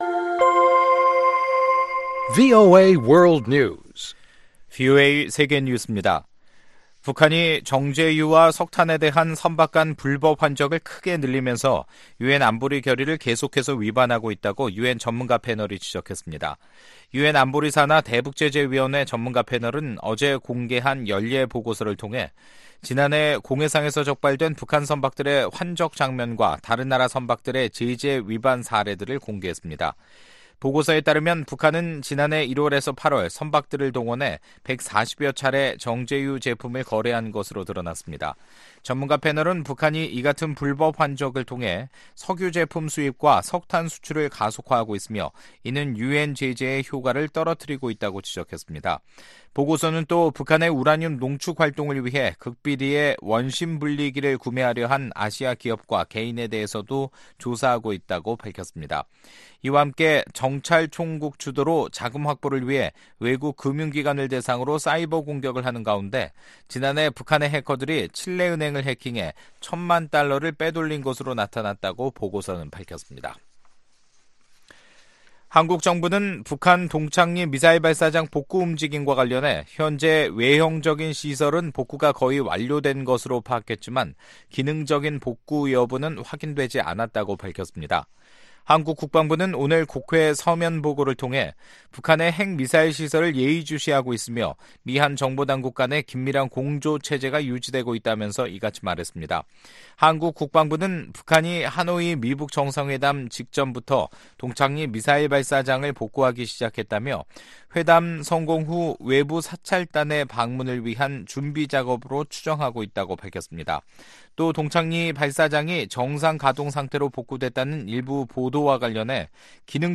VOA 한국어 간판 뉴스 프로그램 '뉴스 투데이', 2019년 3월 13일 2부 방송입니다. 북한이 선박들을 동원해 140여 차례 정제유 제품을 거래하고, 영변 핵시설을 가동 중이라고 유엔 보고서가 밝혔습니다. 미국은 북한의 완전한 비핵화가 실현될 때까지 북한에 대한 압박을 유지할 것이라고 재무부 고위 관리가 말했습니다.